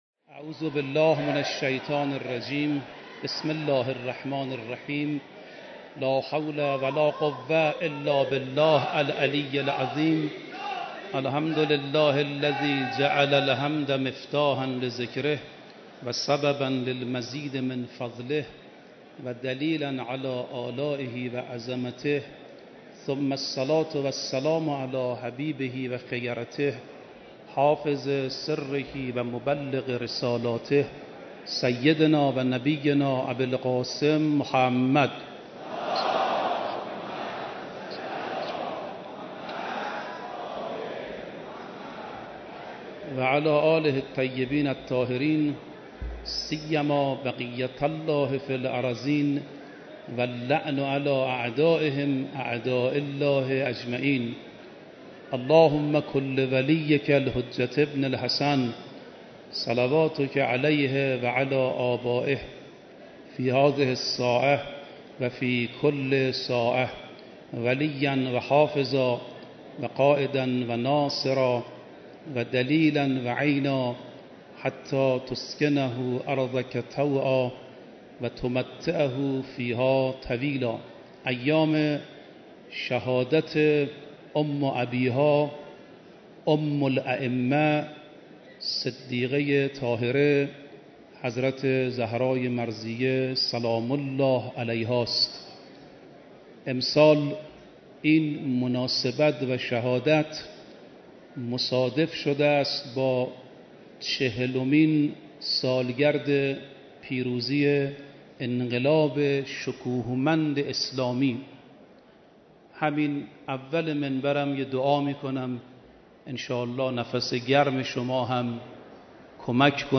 سومین شب مراسم عزاداری شهادت حضرت فاطمه زهرا سلام‌الله‌علیها
سخنرانی